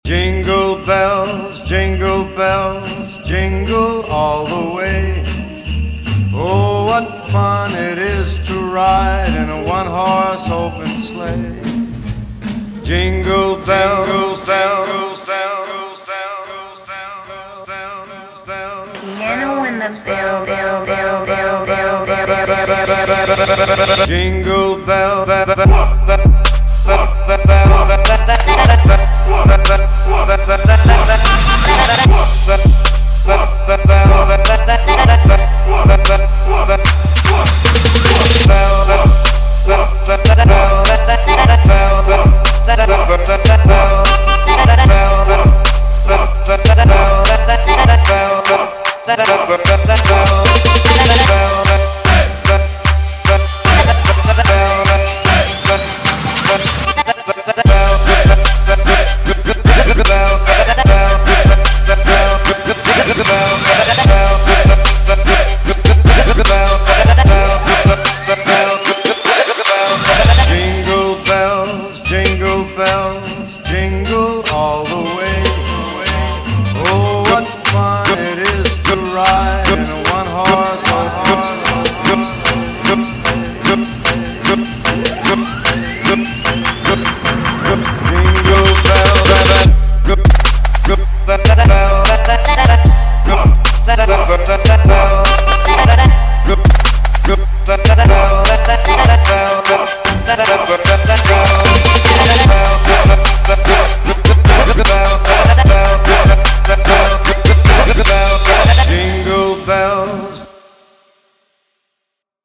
вот норм трапчик